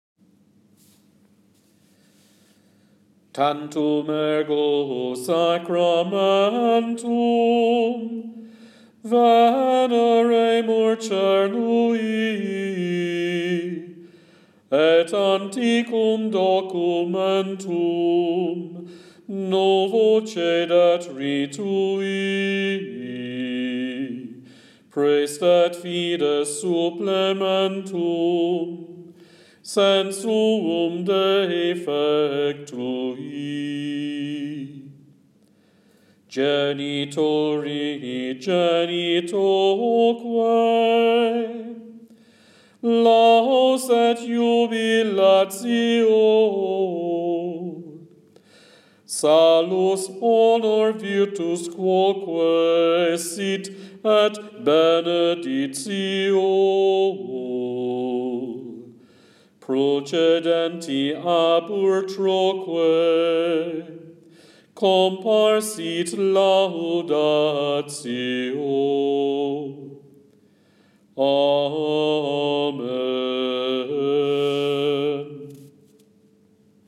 Hymn Latin